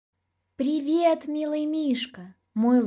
Аудиокнига Плюша | Библиотека аудиокниг